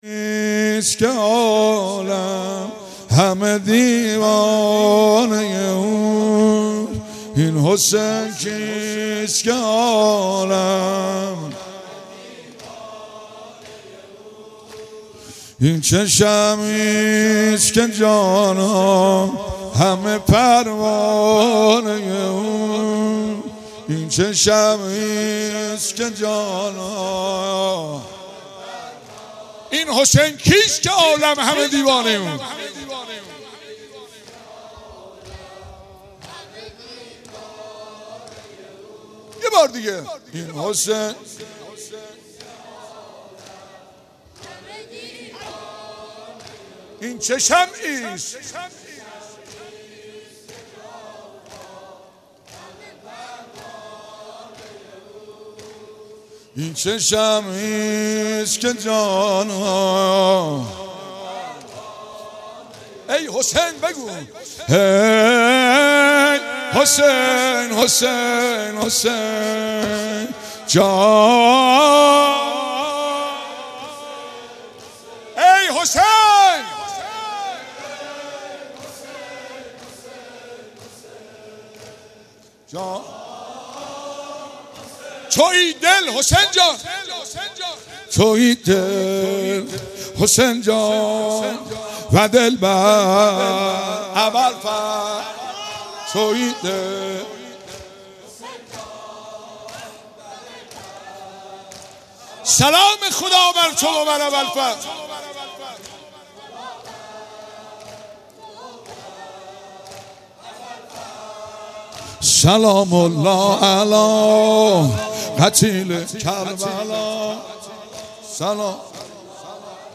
شب سوم ماه مبارک رمضان در حرم حضرت معصومه سلام الله علیها